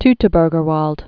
(ttə-bûrgər wôld, toitō-brgər vält)